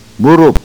To hear proper pronunciation, click one of the links below
Moo Roop Knee